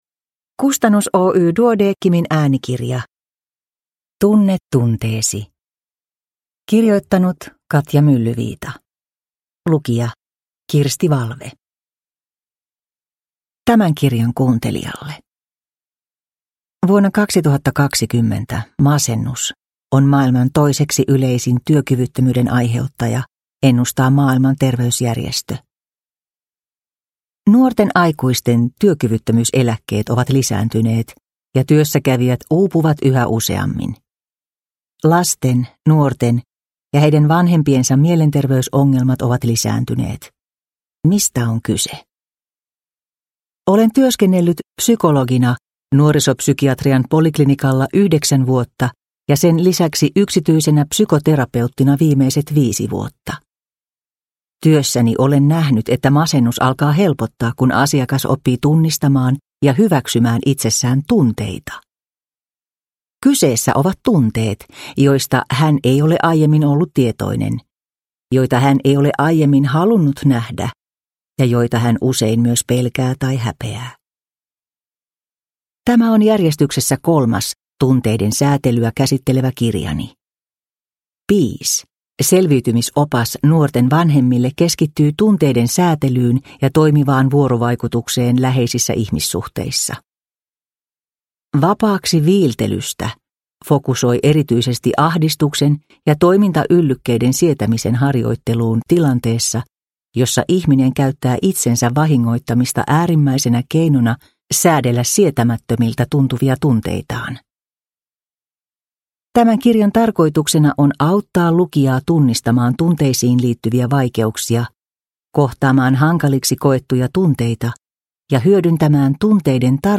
Tunne tunteesi – Ljudbok – Laddas ner